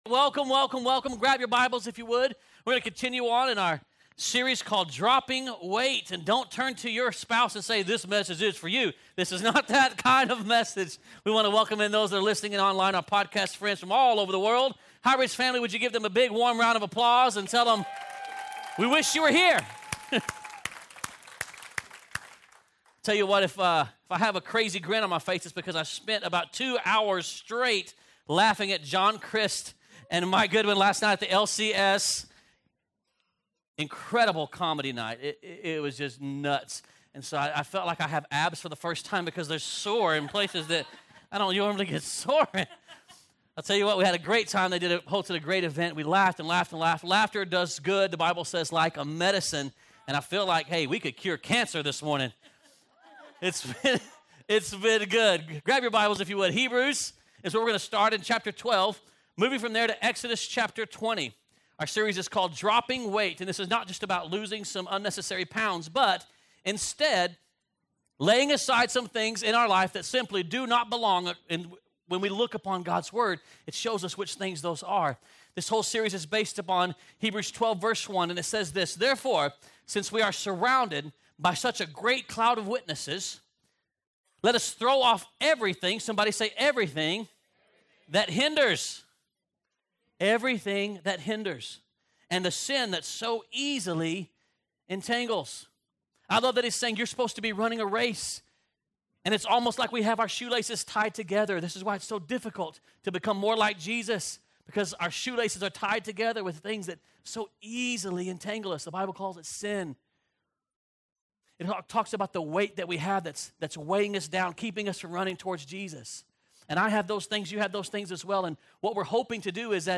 2019 Sermon